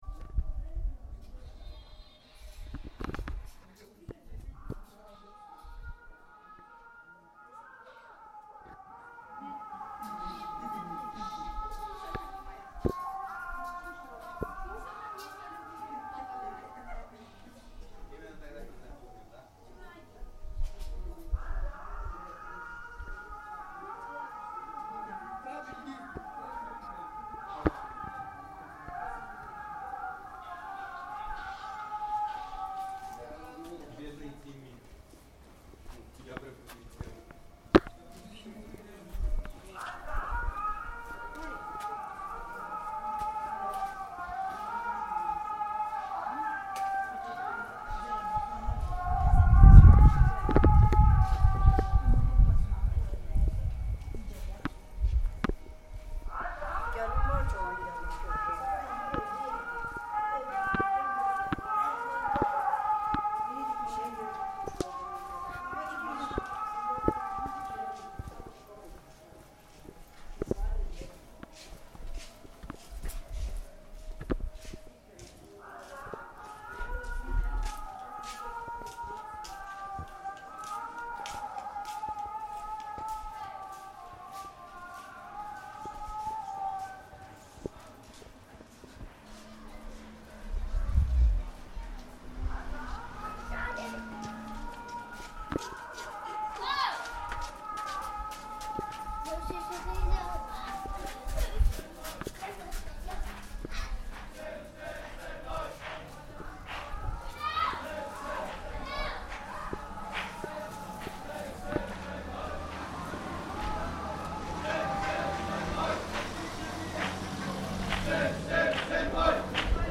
Singing and religious procession in the ancient city
On this recording, you can hear the mullah's call to the Juma mosque. It was a special Muslim Ashura day. The procession of the branches of Islam to the mosque for the holiday is heard.
UNESCO listing: Citadel, Ancient City and Fortress Buildings of Derbent